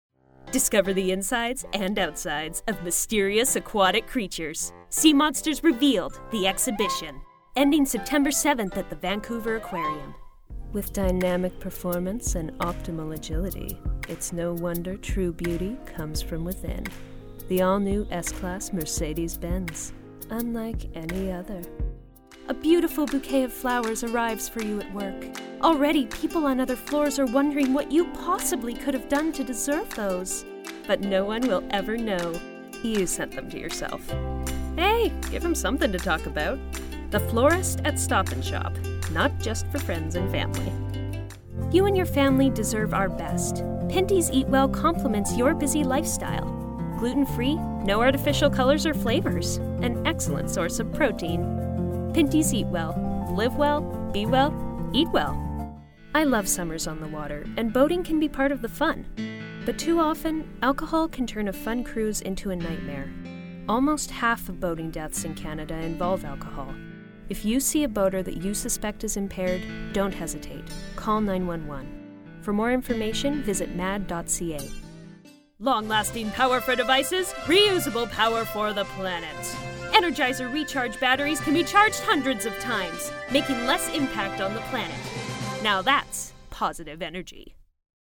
20 Something , 30 Something , Bright , Female